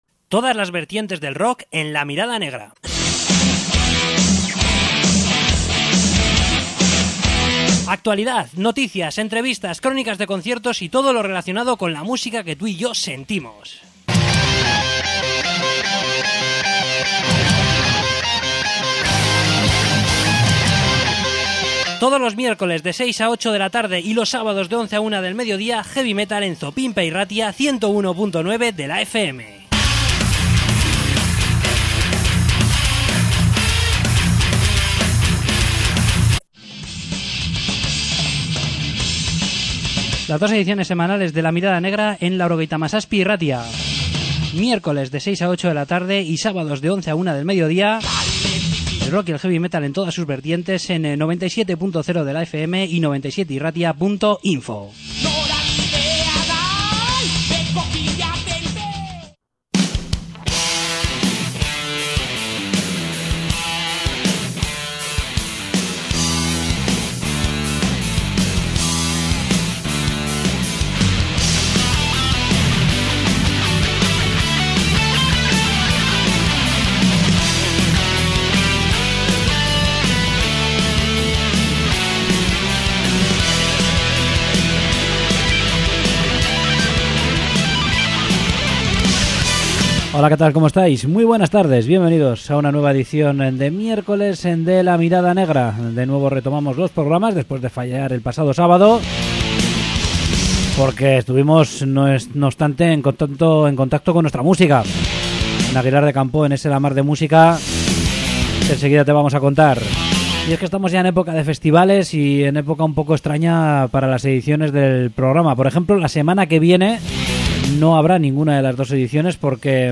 Entrevista con Deaf Devils
Entrevista con Opera Magna